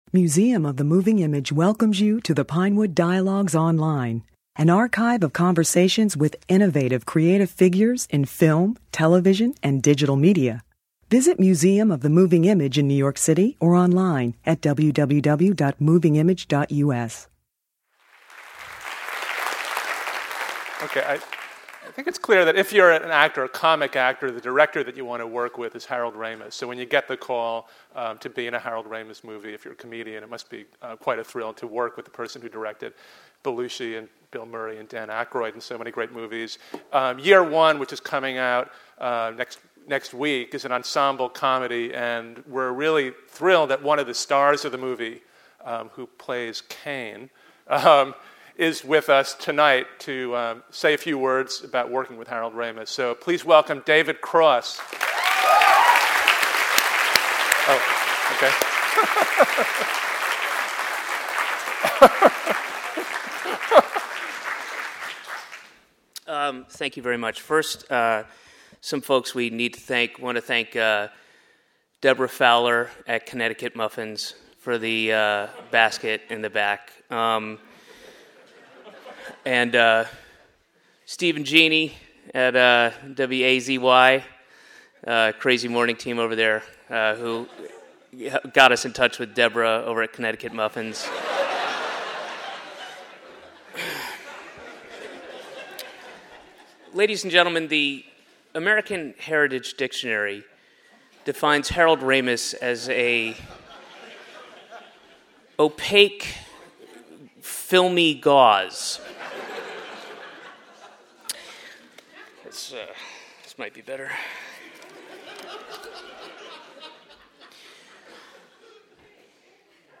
In this special Museum of the Moving Image program, Ramis talked about his career, starting with his days as head writer of the series Second City TV. Comedian and actor David Cross, who co-stars in Year One, introduced Ramis.